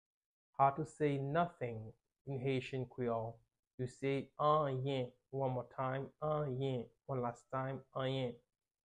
How to say "Nothing" in Haitian Creole - "Anyen" pronunciation by a native Haitian Teacher
“Anyen” Pronunciation in Haitian Creole by a native Haitian can be heard in the audio here or in the video below:
How-to-say-Nothing-in-Haitian-Creole-Anyen-pronunciation-by-a-native-Haitian-Teacher.mp3